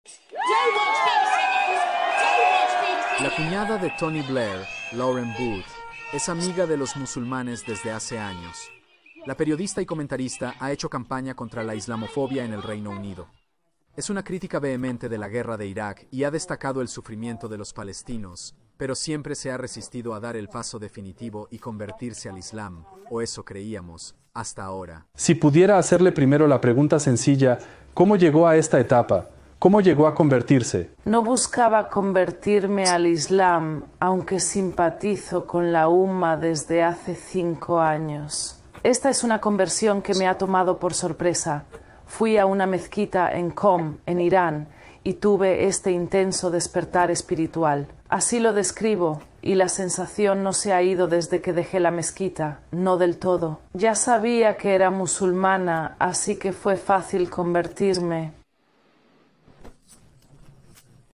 Descripción: En este video, Lauren Booth, cuñada de Tony Blair, comparte su historia de conversión al Islam.